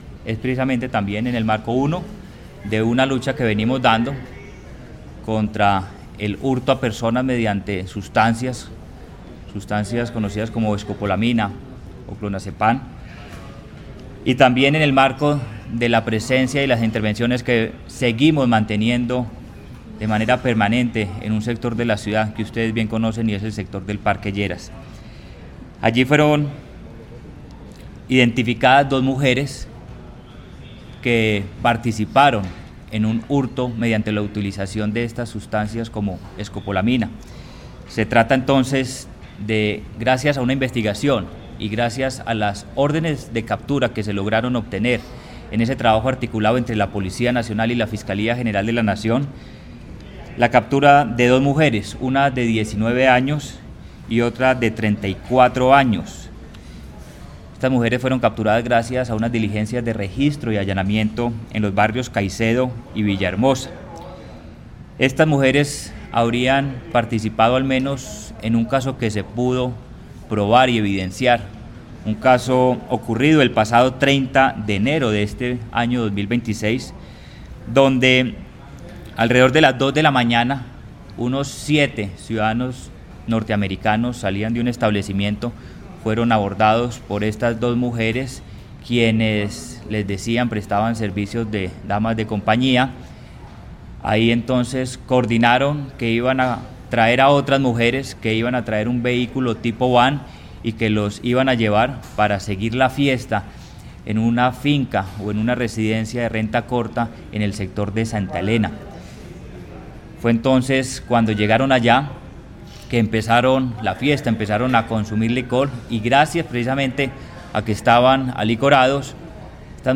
Declaraciones del secretario de Seguridad y Convivencia, Manuel Villa Mejía
Declaraciones-del-secretario-de-Seguridad-y-Convivencia-Manuel-Villa-Mejia-1.mp3